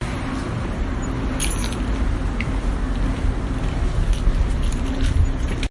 钥匙的叮当声
描述：钥匙被叮当作响。
标签： 叮当 键 - 丁当 多键 叮当 钥匙 多个声音
声道立体声